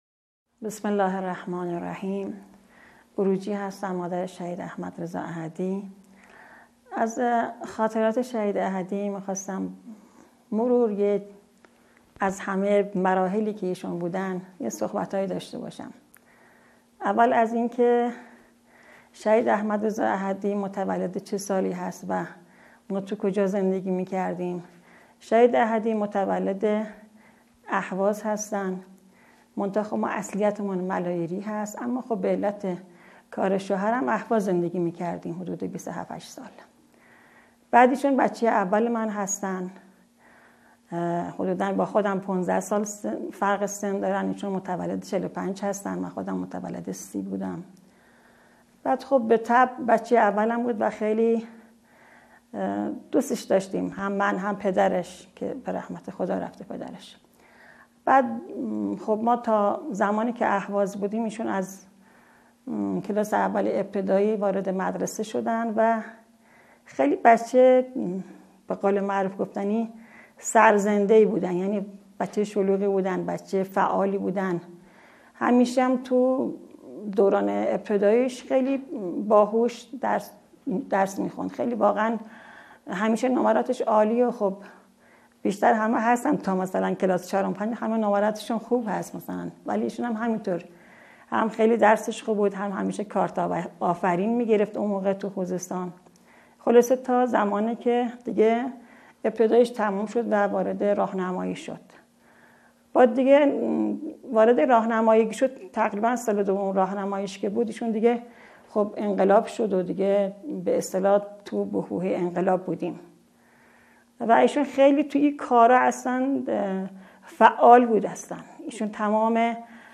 صوت/گفتگو